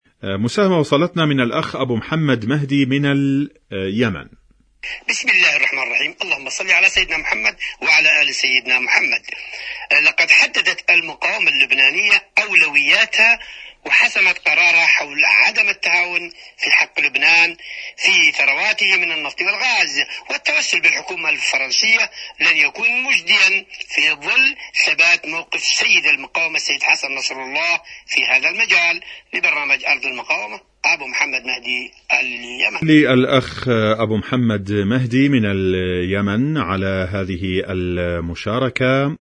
مشاركة واتساب صوتية